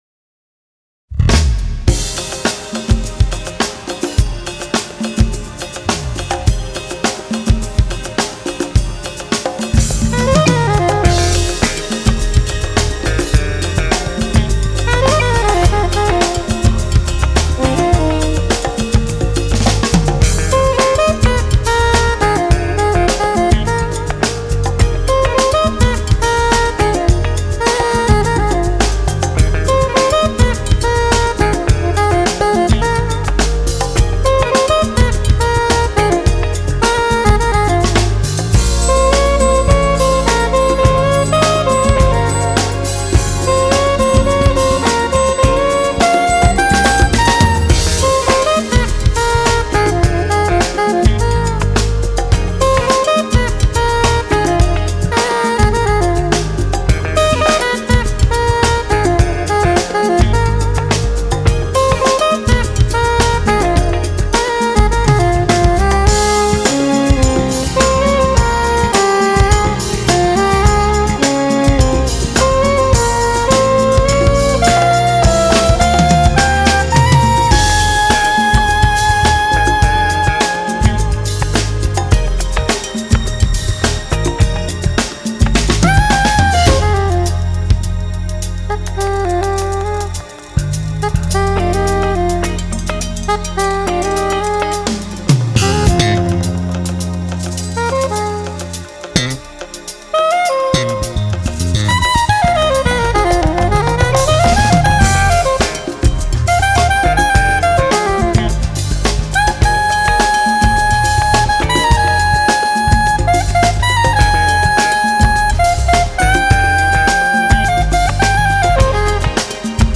音樂類別 : 薩克斯風
很優雅的薩克斯風音樂，感謝版主分享!